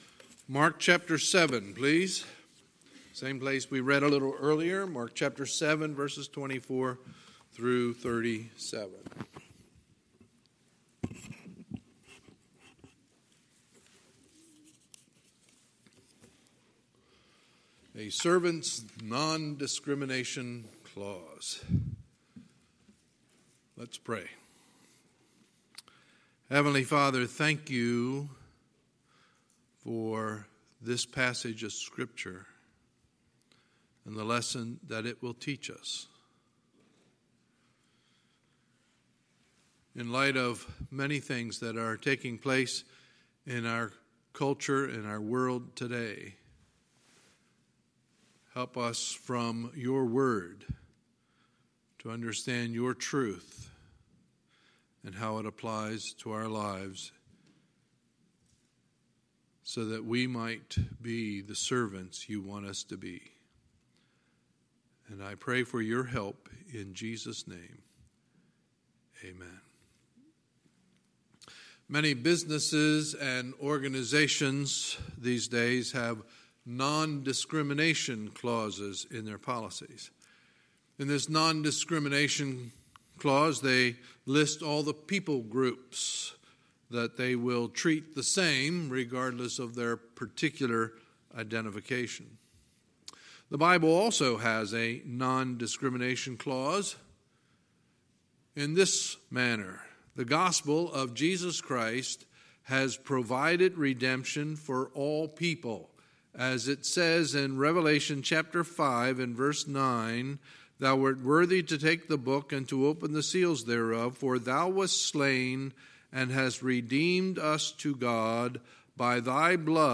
Sunday, July 21, 2019 – Sunday Morning Service